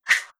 Close Combat Swing Sound 56.wav